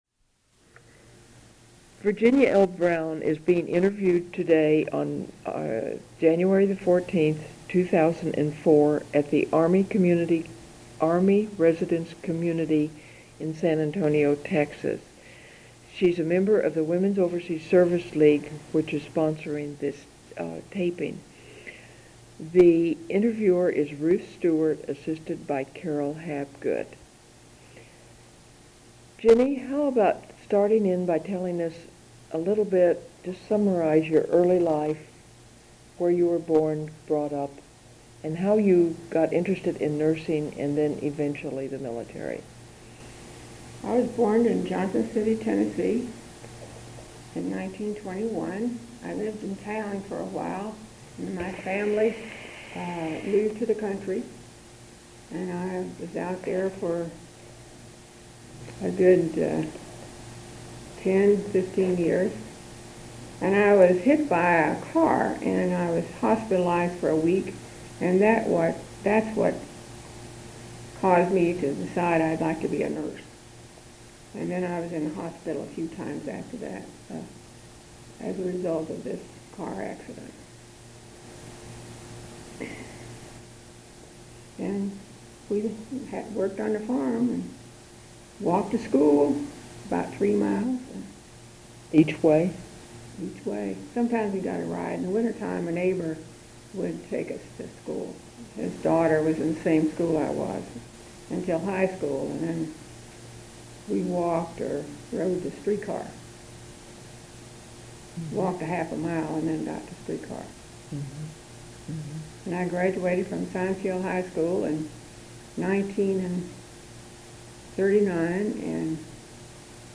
Oral History Project
Sound recordings Interviews